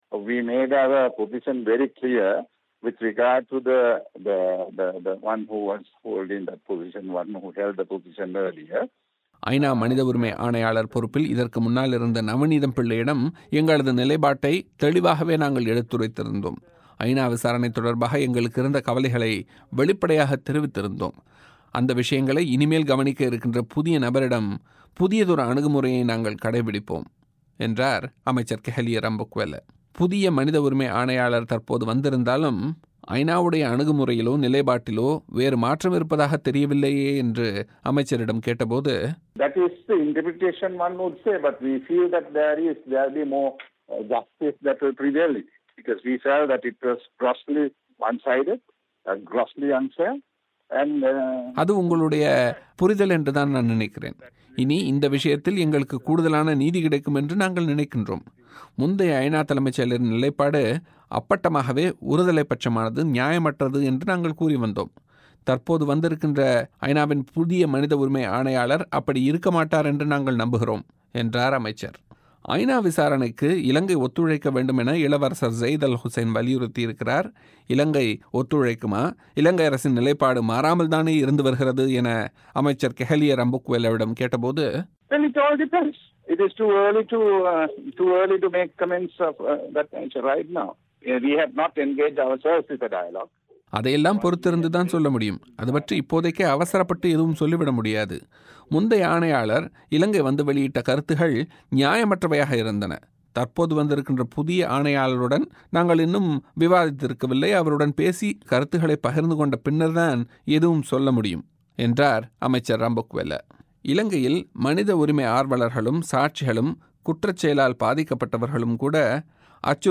ஐநாவின் புதிய மனித உரிமை ஆணையாளராக வந்துள்ள இளவரசர் ஸெய்த் அல் ஹுசைனுடன் இலங்கை விவகாரத்தை விவாதிக்க அரசாங்கம் தயாராக இருப்பதாக இலங்கை ஊடகத்துறை அமைச்சர் கெஹெலிய ரம்புக்வெல்ல பிபிசியிடம் தெரிவித்துள்ளார்.